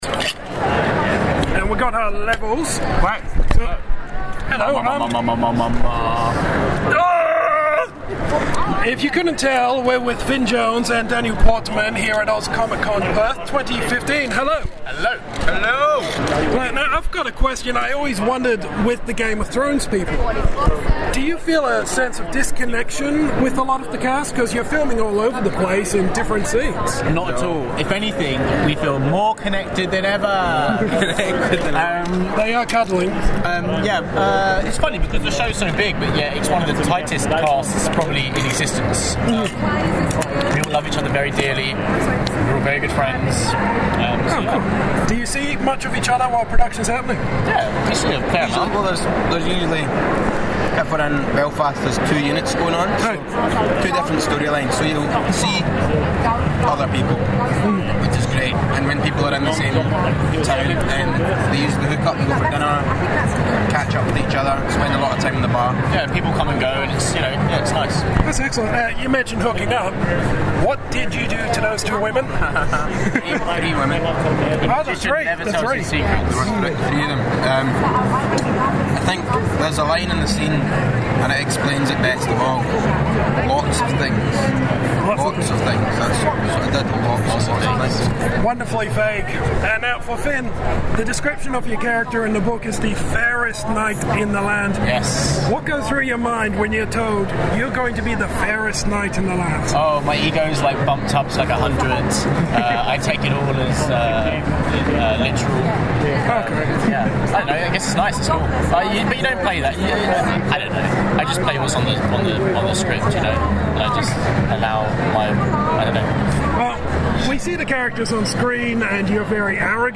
Exclusive Interview with Finn Jones and Daniel Portman from ‘Game of Thrones’!
At Oz ComicCon this weekend we sat down with Daniel Portman, who plays Podrick Payne and Finn Jones, who plays Loras ‘Knight of Flowers’ Tyrell.